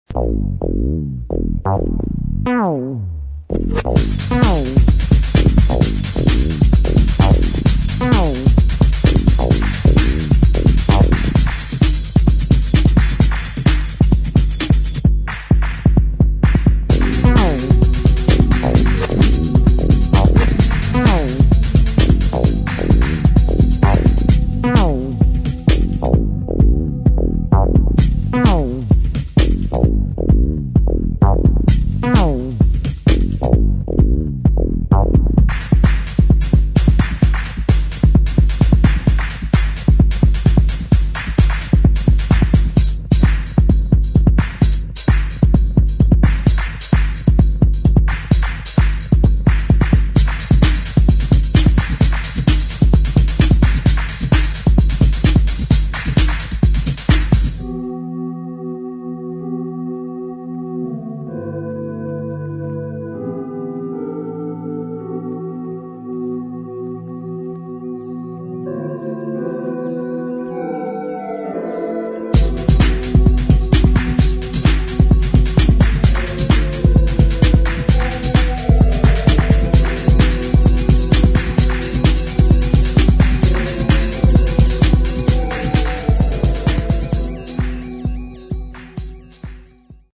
[ TECHNO / ELECTRONIC ]